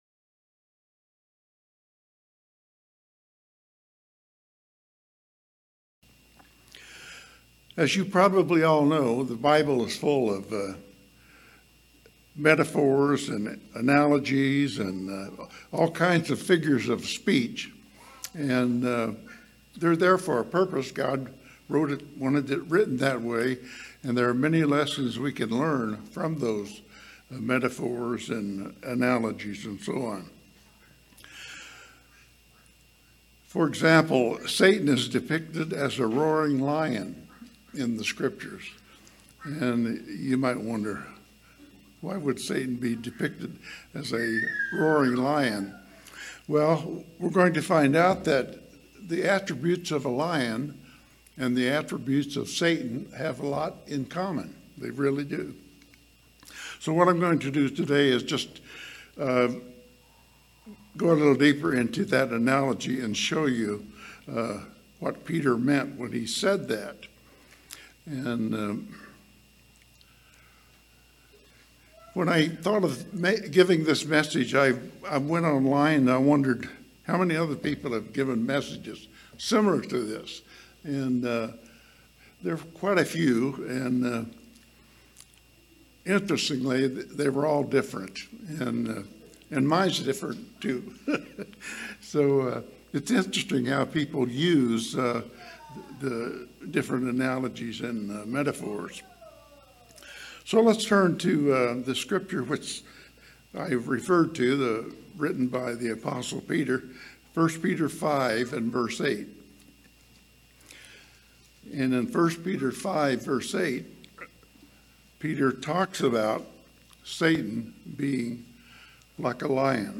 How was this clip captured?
Given in Las Vegas, NV Redlands, CA San Diego, CA